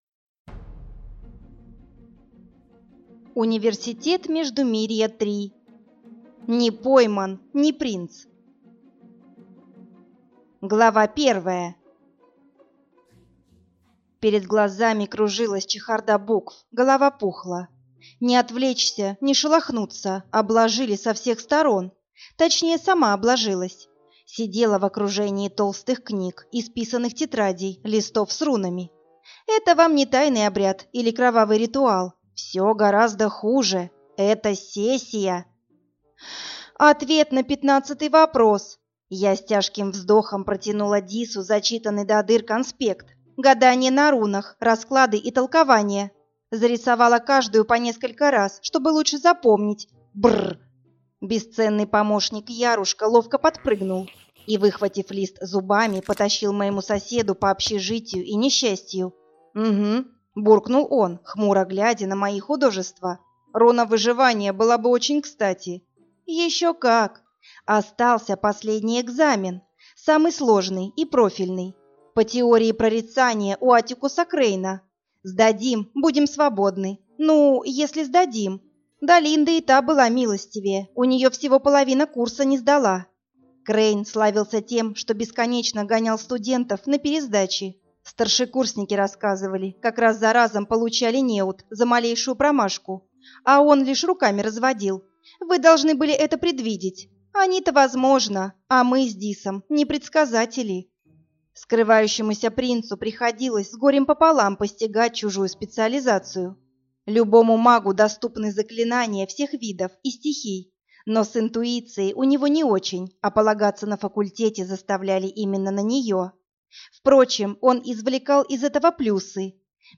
Аудиокнига Университет Междумирья. Не пойман – не принц | Библиотека аудиокниг